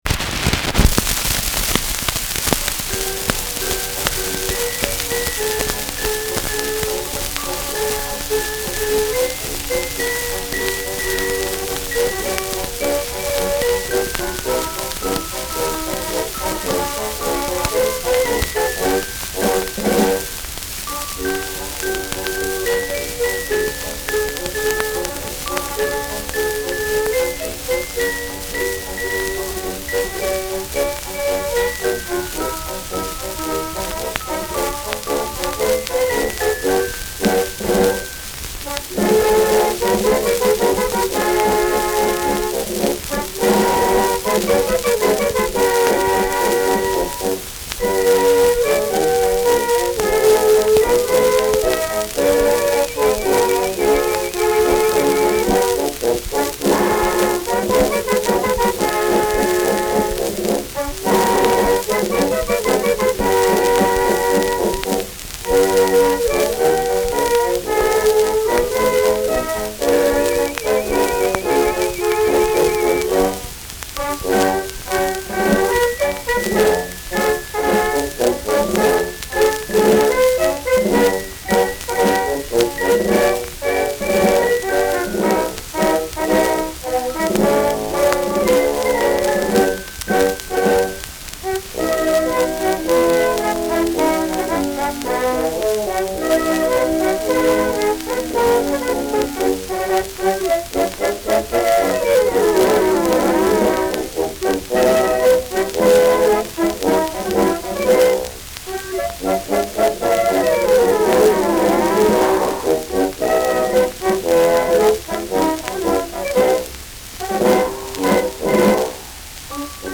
Schellackplatte
Stark abgespielt : Anfang stark verrauscht : Vereinzelt stärkeres Knacken